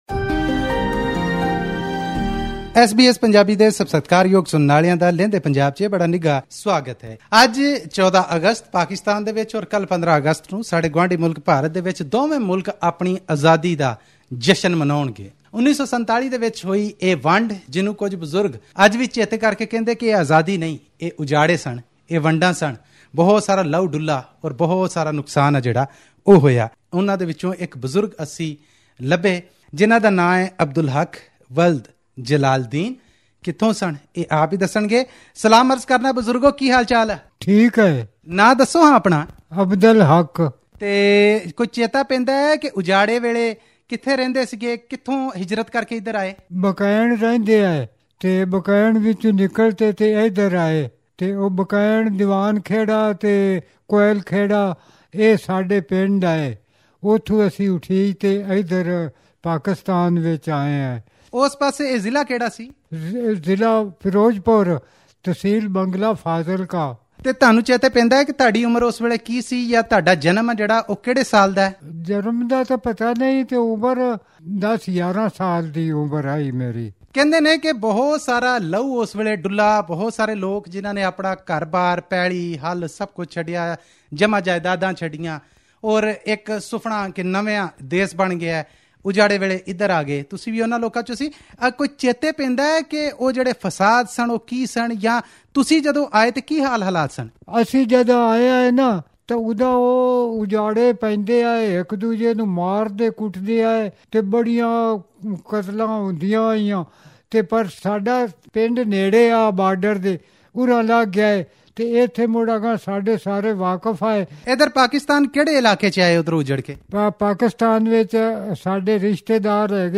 Listen to his eyewitness account by clicking on the picture at the top.